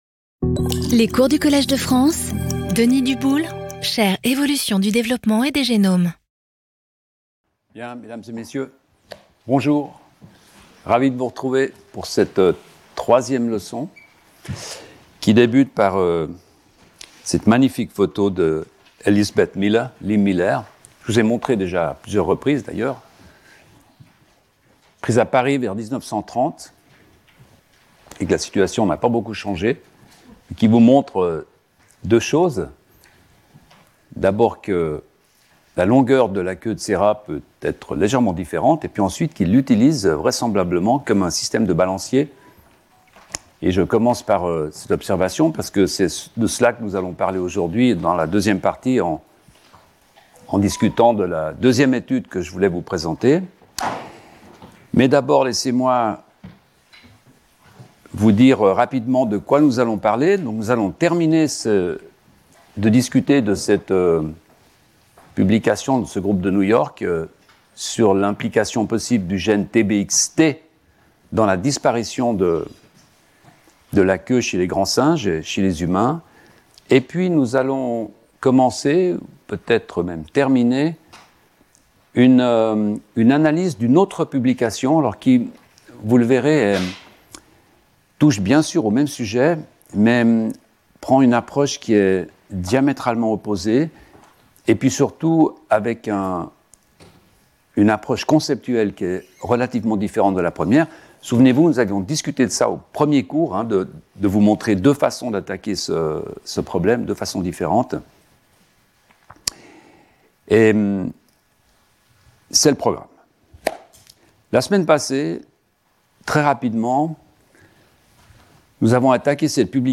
Cette troisième leçon commence par un bref rappel de quelques points clés développés dans la leçon 2, concernant en particulier l’étude d’une publication récente mettant en cause le gène Tbxt dans la perte de la queue chez les grands singes, il y a à peu près 25 millions d’années.